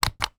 DeltaVR/DeltaVRFMOD/Assets/New_UI/Communications, Transceiver, CB Radio, Talk Button, Press, Release SND33082 1.wav at 36d6ff6d9b2531cc98427ea7a1eb0c963776c268